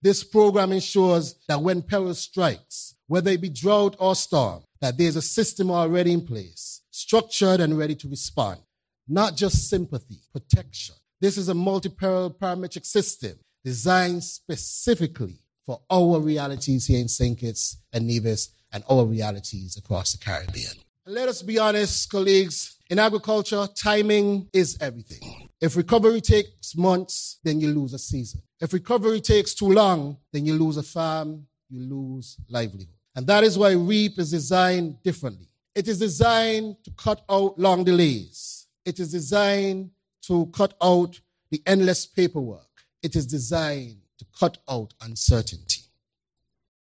The programme was officially introduced on March 17th, during a ceremony at the St. Kitts Marriott Resort.
Federal Minister of Agriculture, the Hon. Samal Duggins gave these remarks: